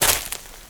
STEPS Leaves, Walk 02.wav